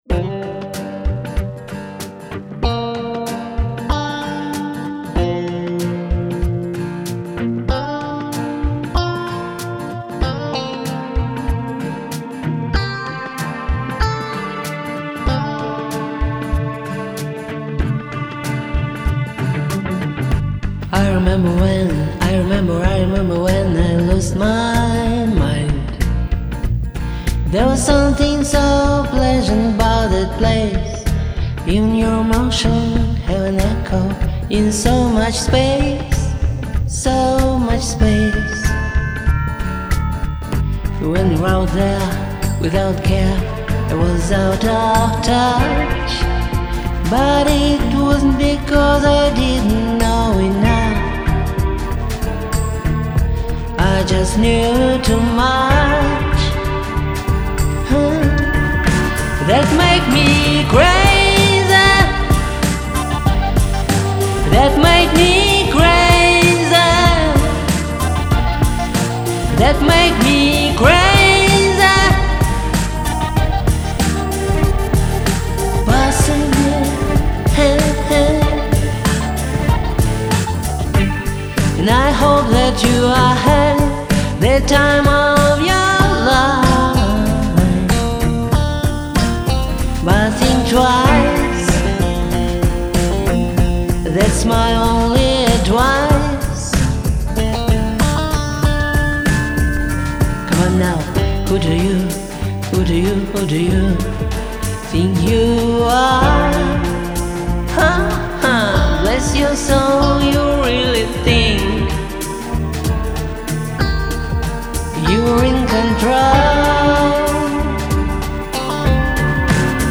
Девчонки - у обеих косяки в интонировании.
старая запись... могла бы перепеть заново....